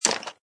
icestone3.mp3